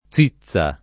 zizza
vai all'elenco alfabetico delle voci ingrandisci il carattere 100% rimpicciolisci il carattere stampa invia tramite posta elettronica codividi su Facebook zizza [ +Z&ZZ a ] o cizza [ ©&ZZ a ] s. f. — voce ant. o region. per «mammella»